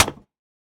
Minecraft Version Minecraft Version latest Latest Release | Latest Snapshot latest / assets / minecraft / sounds / block / mud_bricks / break5.ogg Compare With Compare With Latest Release | Latest Snapshot